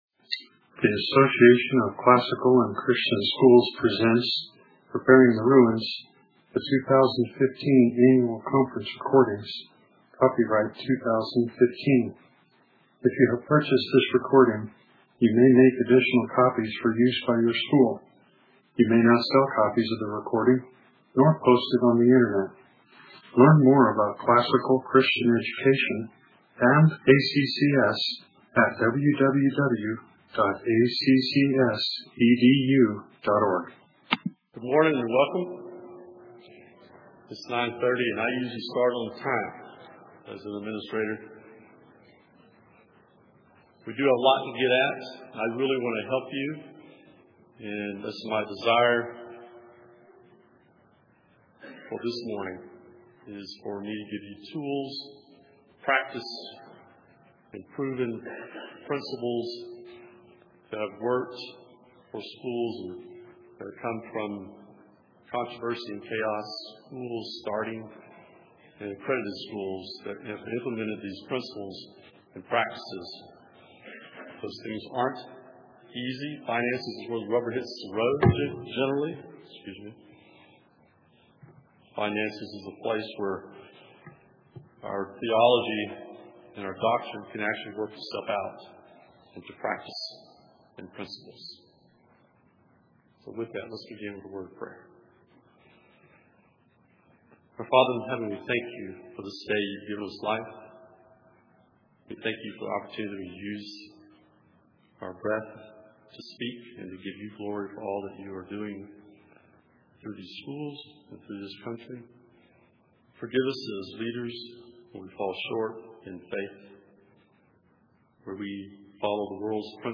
2015 Leaders Day Talk | 0:51:43 | Budgets & Finance, Leadership & Strategic